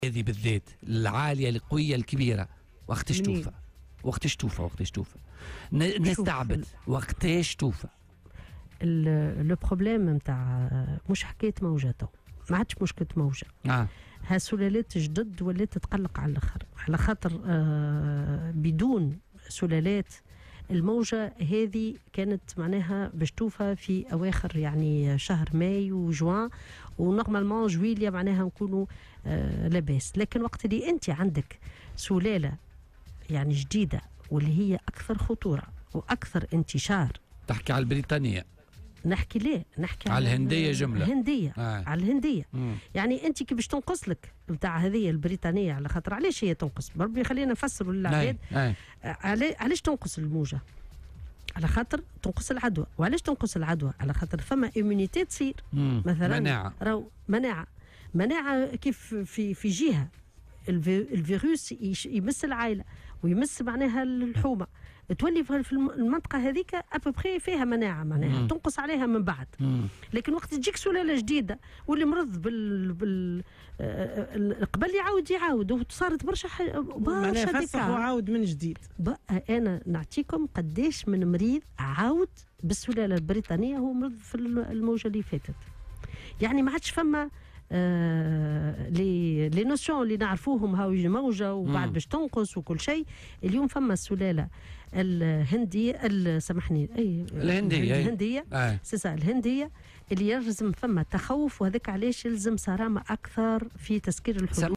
وأوضحت في مداخلة لها اليوم على "الجوهرة أف أم" أن الإشكال لم يعد يتعلّق بمجرّد موجة من الوباء، بل أصبحنا نتحدث عن سلالات جديدة و "مقلقة".